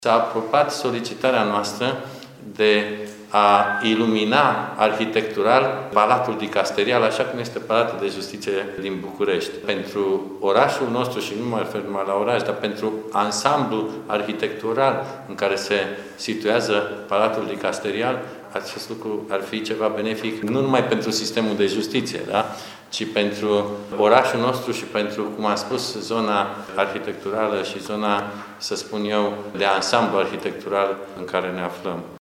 Cu ocazia bilanțului de activitate, președintele Curții de Apel Timișoara a anunțat că se lucrează la un proiect prin care Palatul Dicasterial să fie iluminat arhitectural.